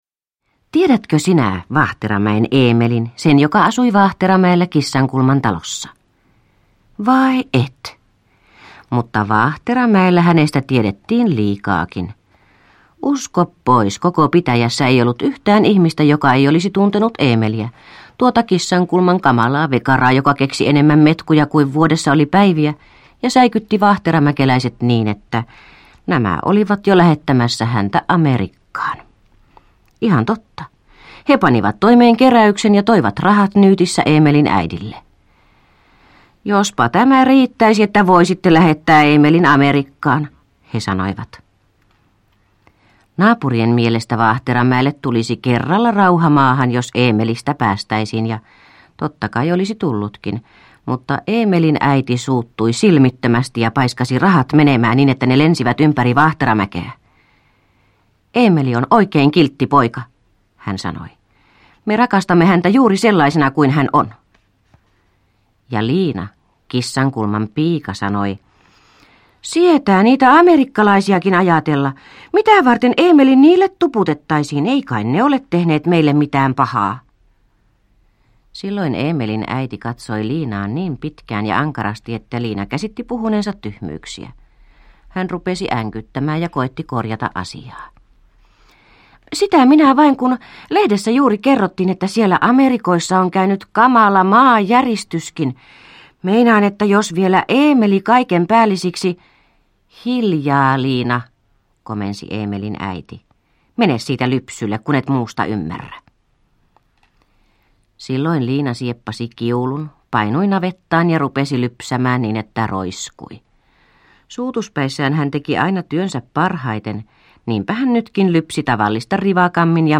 Eemelin uudet metkut – Ljudbok – Laddas ner